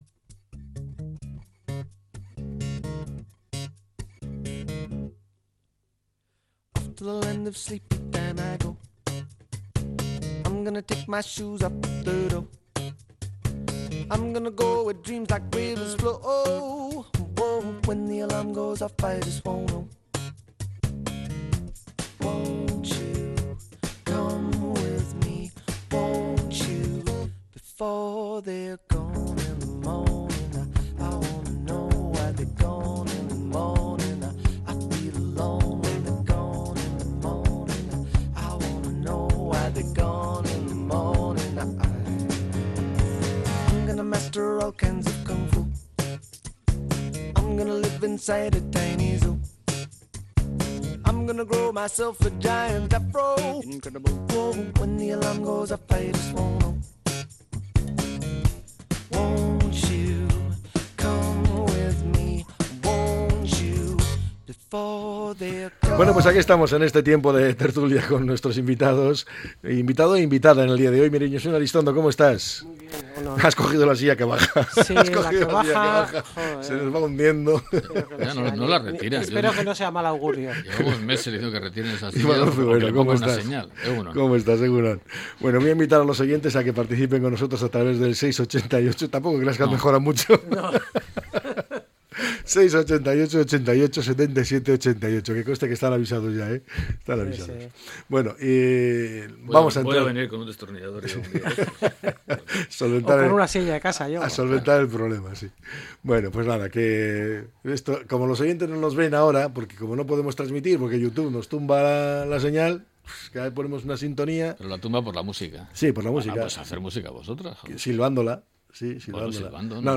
La tertulia 03-02-25.